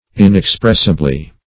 Search Result for " inexpressibly" : The Collaborative International Dictionary of English v.0.48: Inexpressibly \In`ex*press"i*bly\, adv.